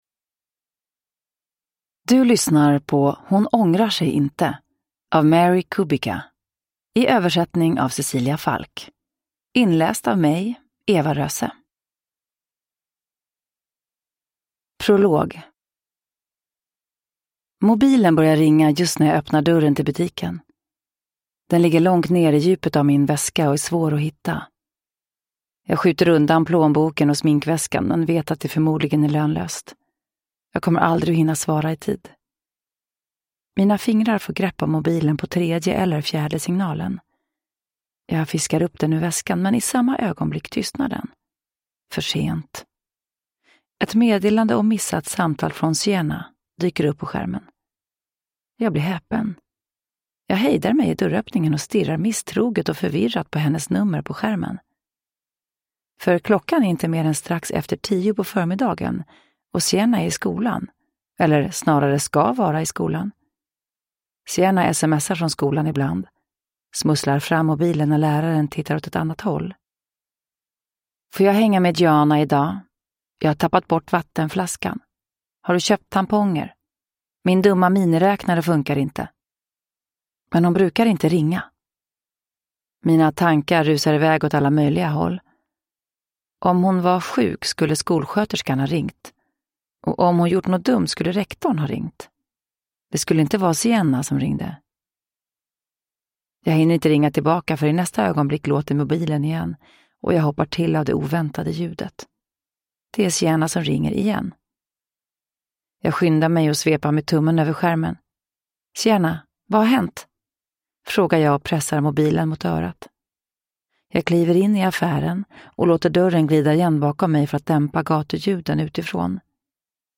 Uppläsare: Eva Röse
Ljudbok